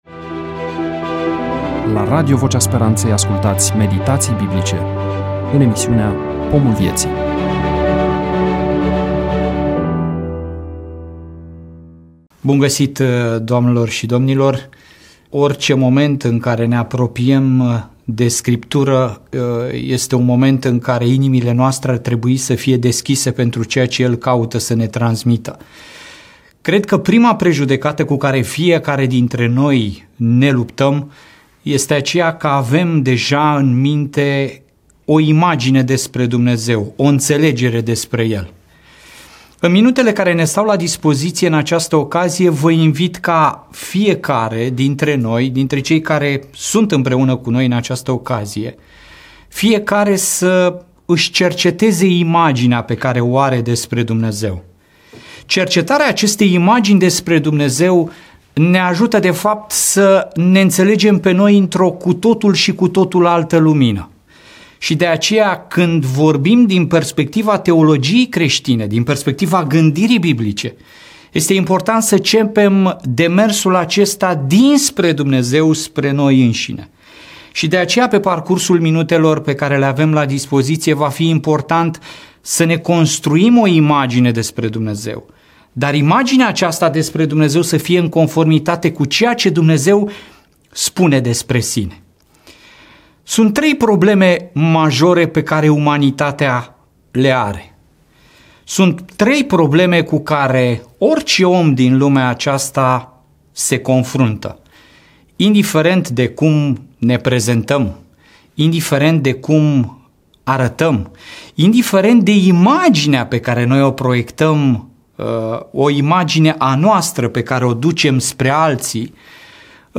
EMISIUNEA: Predică DATA INREGISTRARII: 13.03.2026 VIZUALIZARI: 21